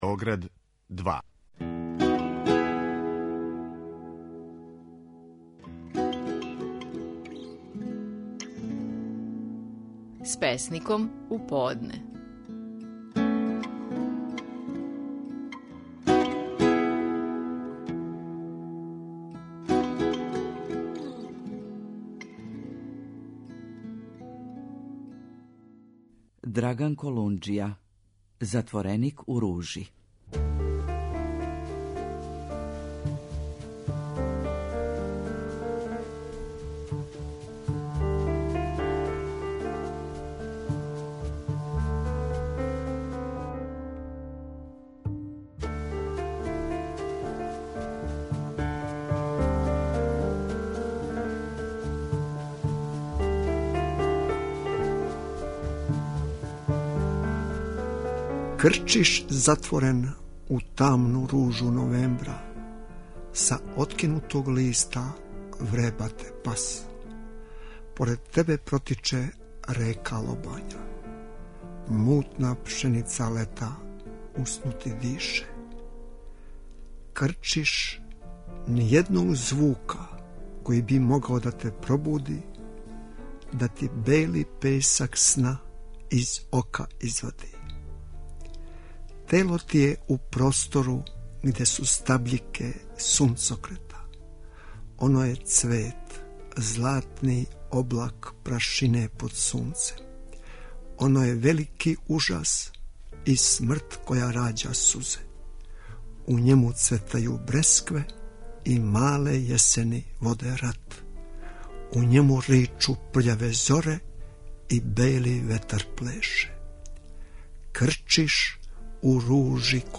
Наши најпознатији песници говоре своје стихове
Своју песму "Затвореник у ружи", данас говори Драган Колунџија.